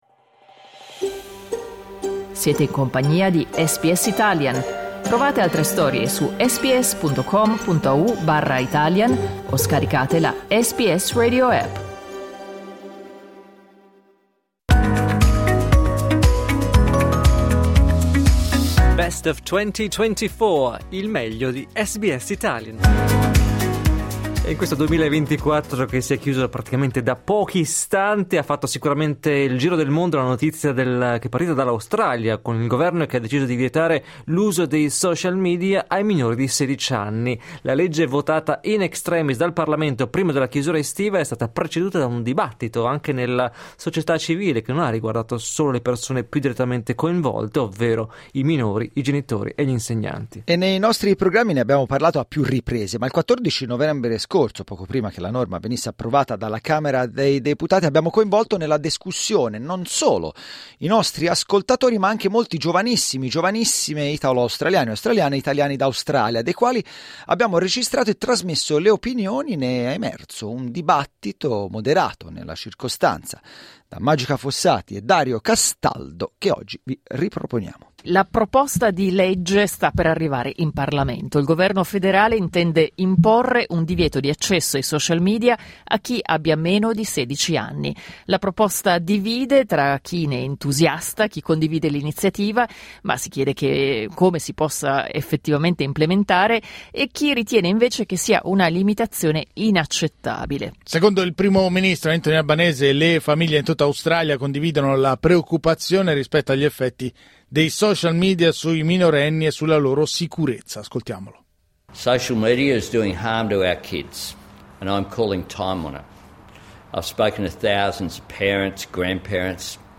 Nei nostri programmi ne abbiamo parlato a più riprese, ma il 14 novembre scorso - poco prima che la norma venisse approvata dalla Camera dei Deputati - abbiamo coinvolto nella discussione non solo i nostri ascoltatori, ma anche molti giovanissimi/e italo-australiani/e e italiani d'Australia, dei quali abbiamo registrato e trasmesso le opinioni.